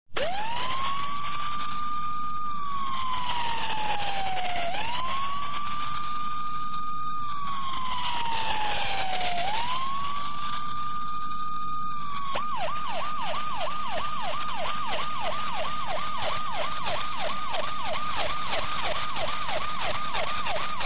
Другие рингтоны по запросу: | Теги: сирена
Категория: Различные звуковые реалтоны